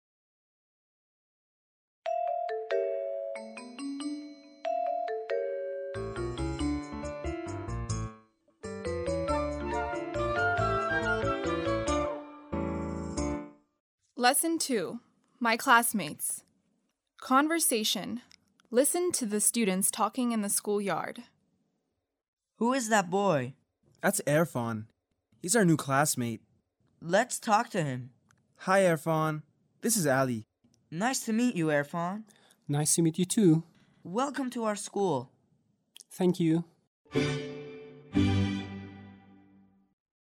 7-Lesson2-Conversation
• 7-Lesson2-Conversation.mp3